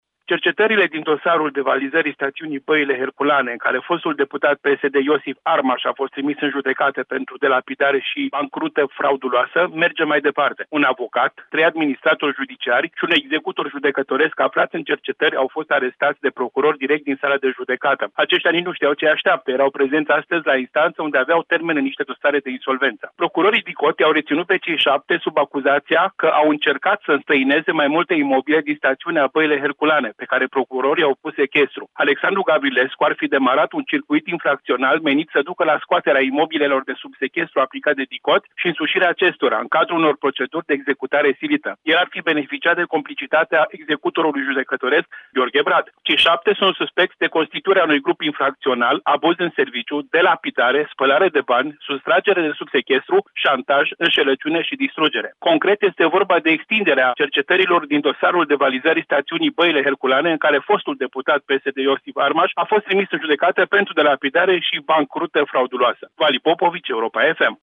Din Caraș Severin, corespondentul Europa FM